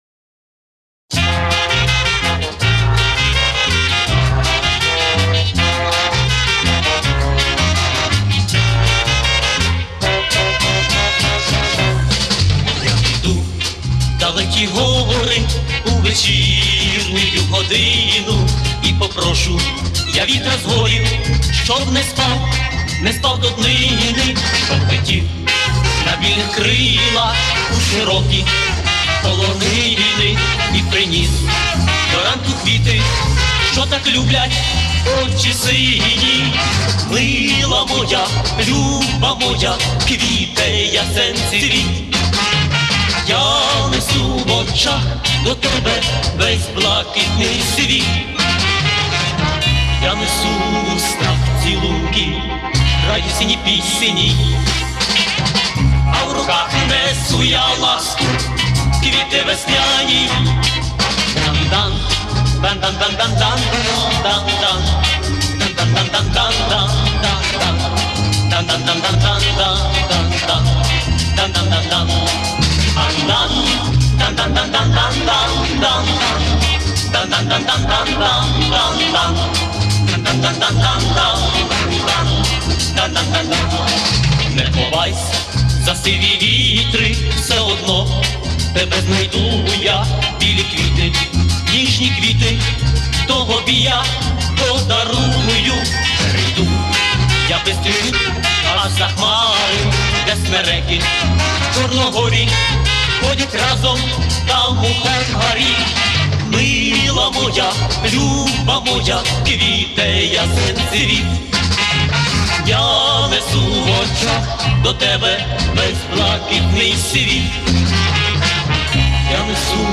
А вот реставрированная.